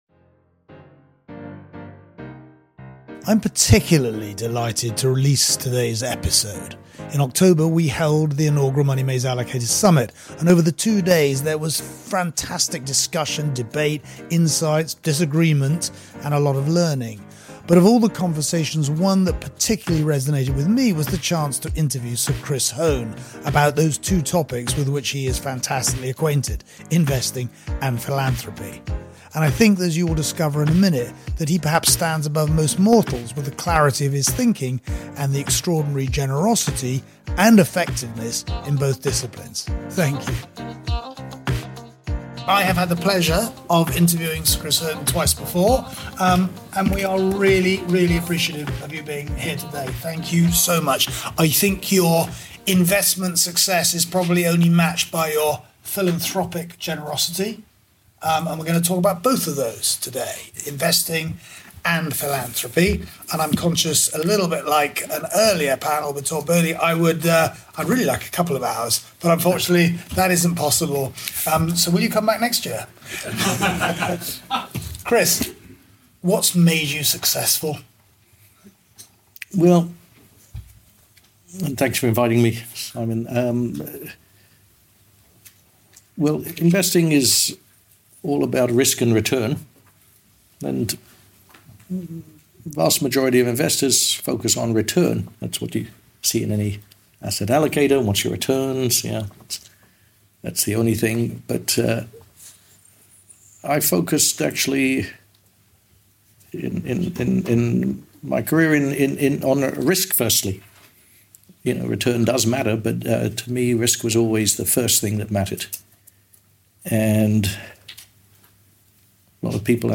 In September we held our inaugural Money Maze Allocator Summit and over the two days there was fantastic discussion, debate, insights, disagreement and a lot of learning.
Of all the conversations, one that particularly resonated with us was the chance to interview Sir Chris Hohn about two topics with which he is fantastically acquainted: investing and philanthropy.